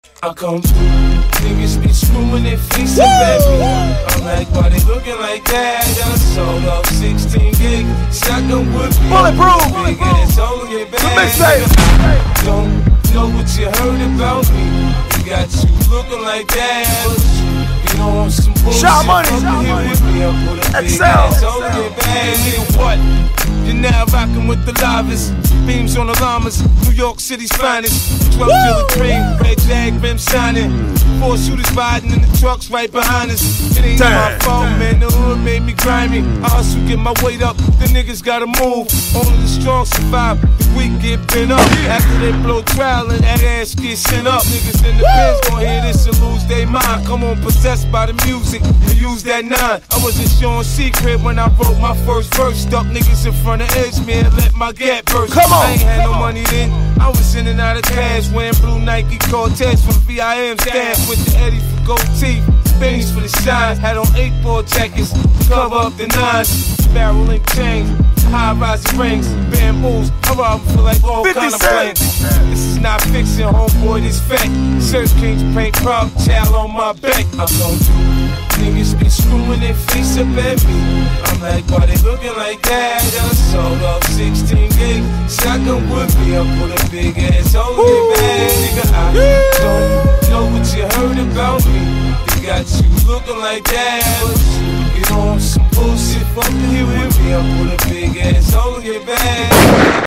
smooth vocals